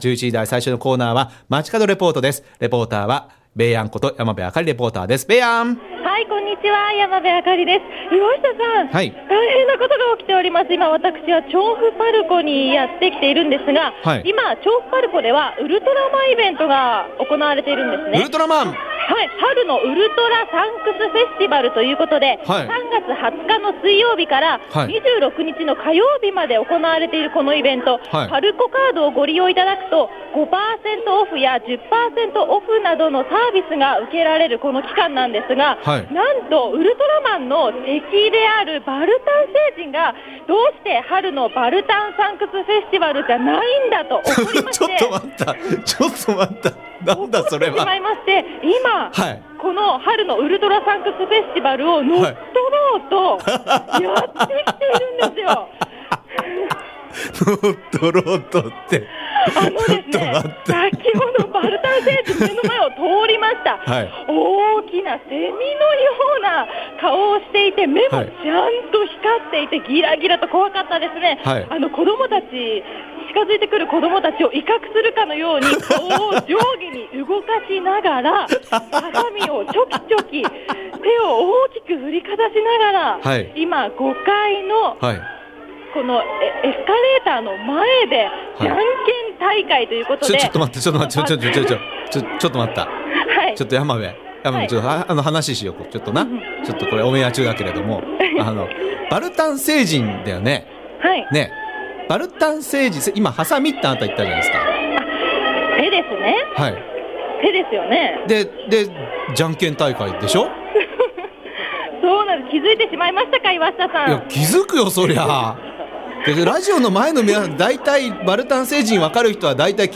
びゅーサン 街角レポート